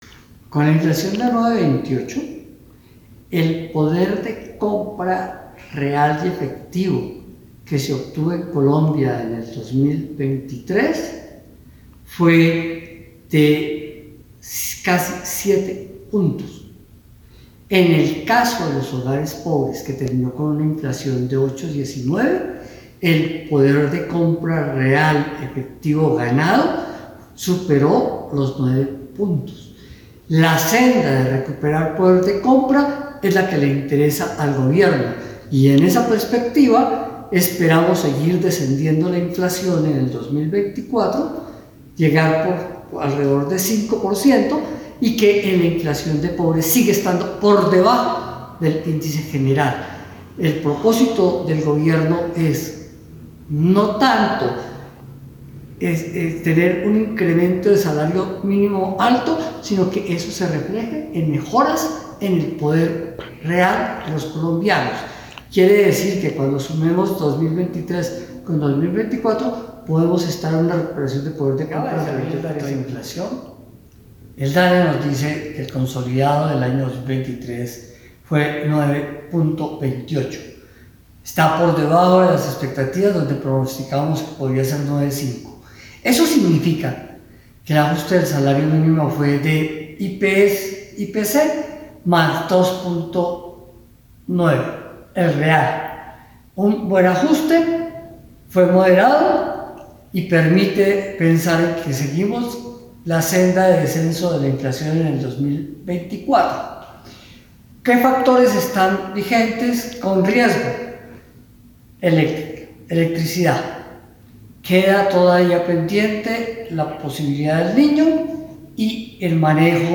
Declaración del Ministro tras conocerse el dato de IPC